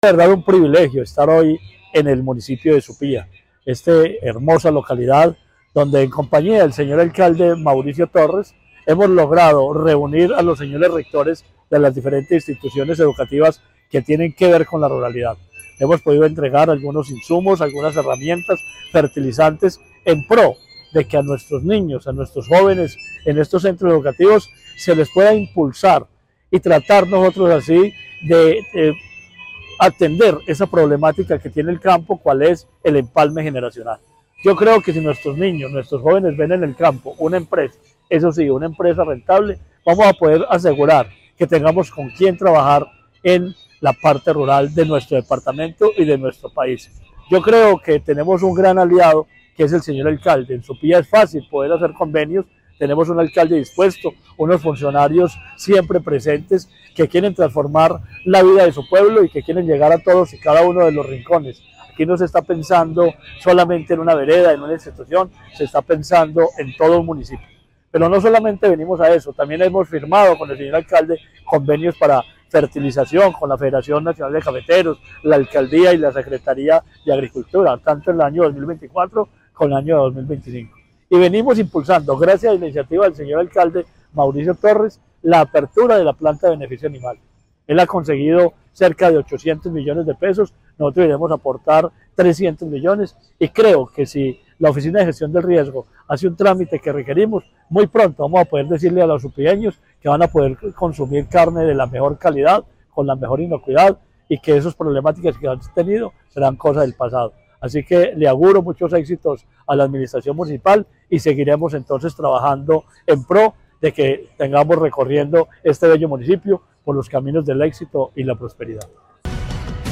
Marino Murillo Franco, secretario de Agricultura y Desarrollo Rural de Caldas.